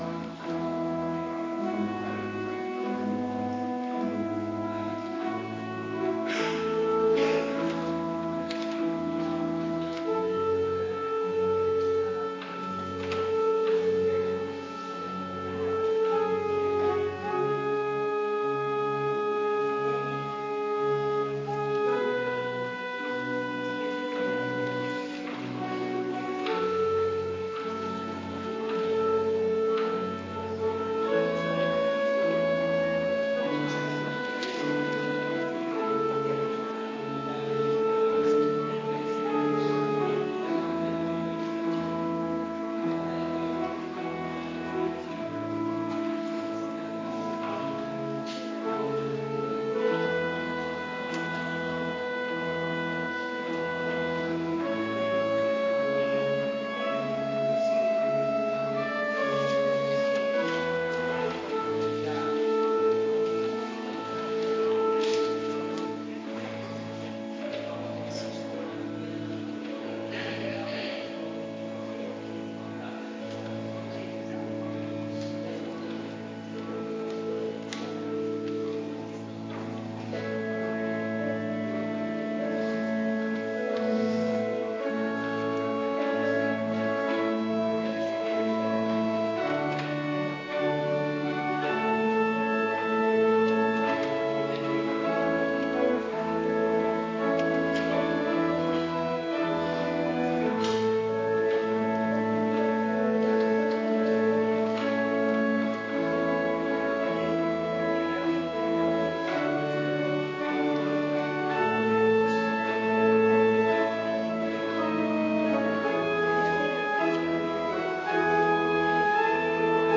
Kerkdiensten